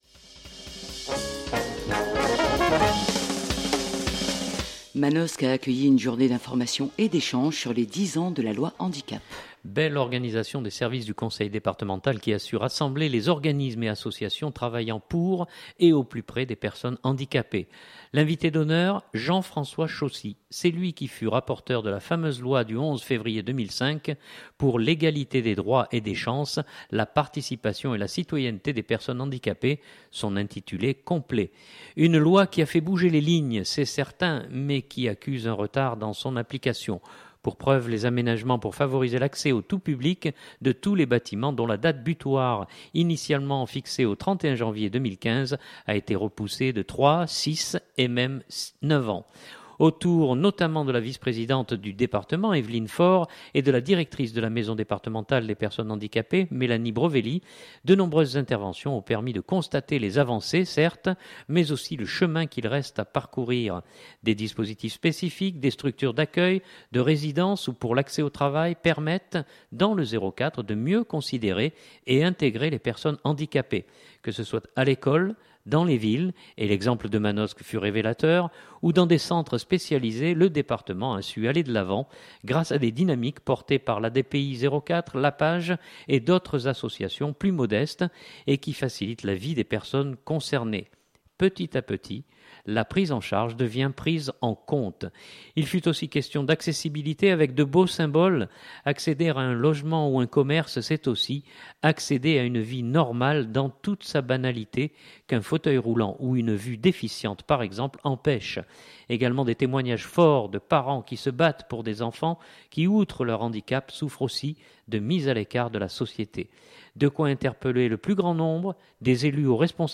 Je vous propose d’écouter Jean-François Chossy qui évoque sans détour les retards et autres problèmes.